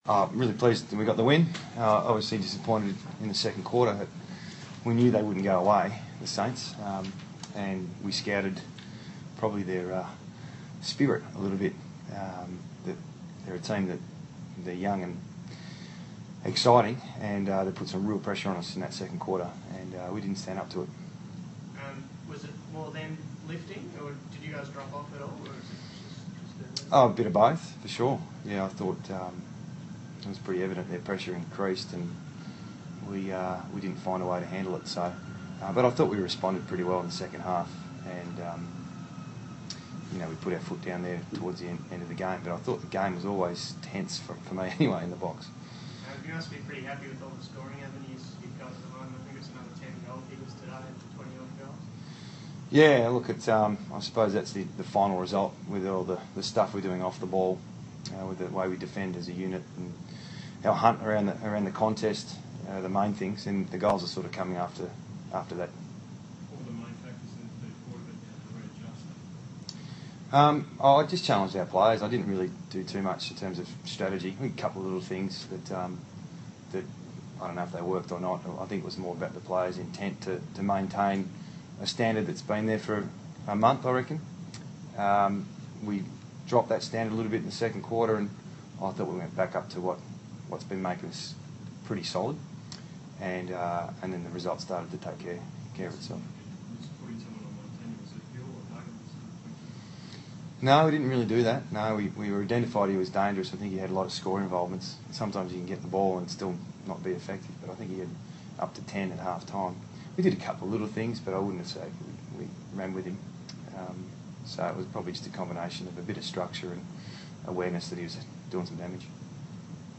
Adam Simpson speaks after the Eagles win over the Saints.